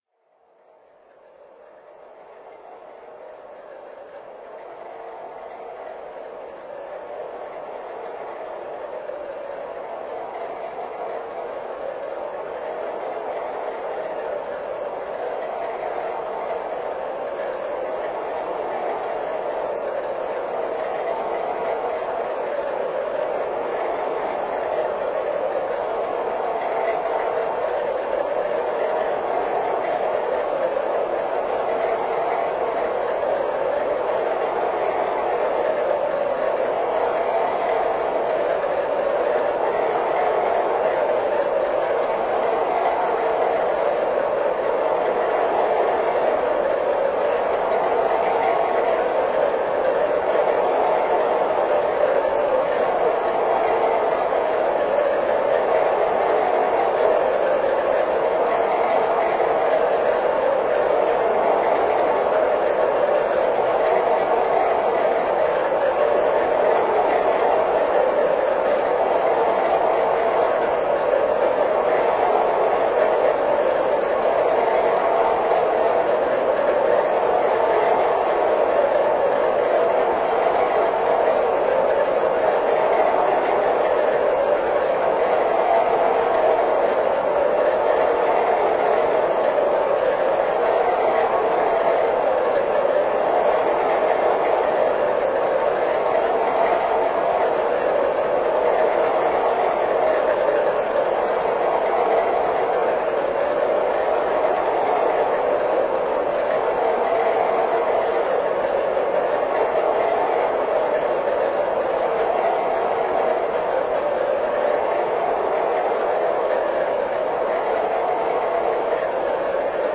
Wind
Electronic wind sound fx.